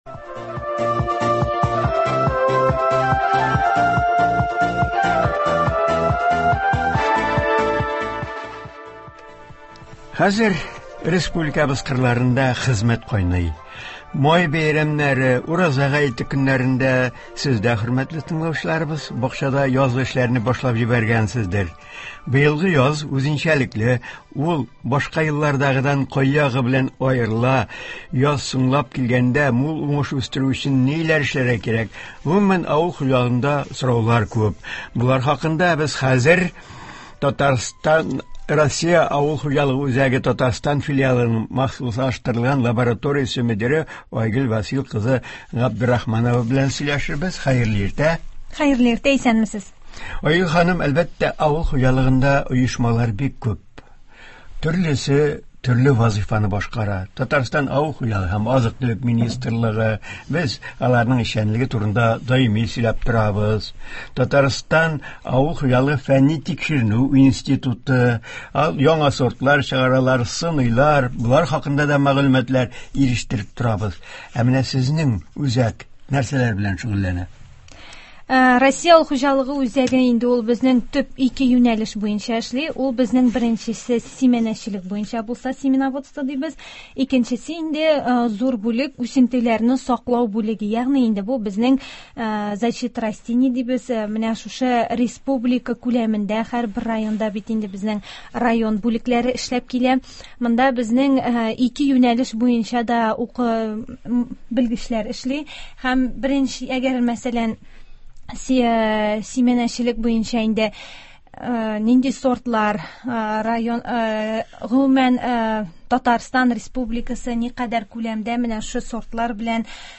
Туры эфир (04.05.22)